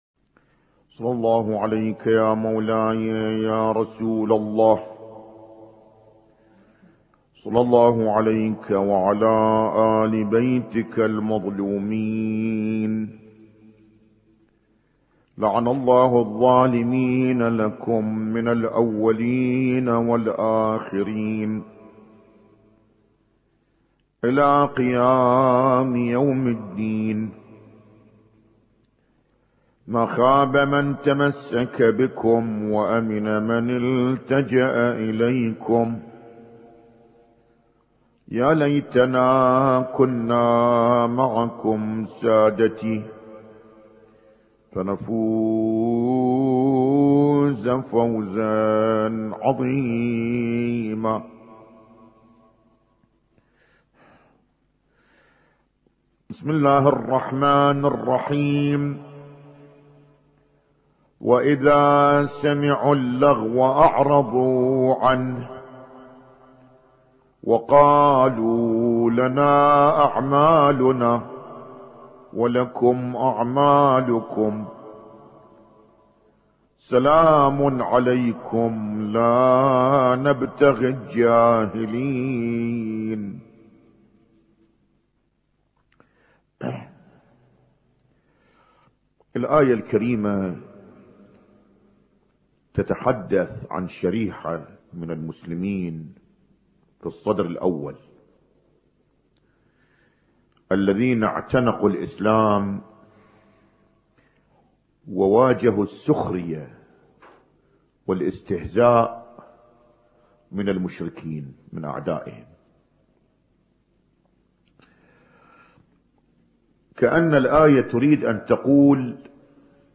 Husainyt Alnoor Rumaithiya Kuwait
اسم التصنيف: المـكتبة الصــوتيه >> المحاضرات >> المحاضرات الاسبوعية ما قبل 1432